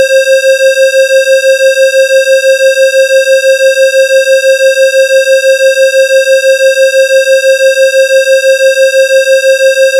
例えば、530Hzの音と536Hzの音を重ねて出すと、1秒間に6回うなるはずです。
上段と中段の波形はそれぞれ、530Hzと536Hzの矩形波です。
合成後の音声を聴くと、確かにうなっていて地下鉄っぽくなっています。
上記では、2つの矩形波を同じ強さで合成しましたが、そうすると、2つの波が打ち消し合うタイミングでは音量が0に近くとなり、音が途切れ途切れとなってしまいます。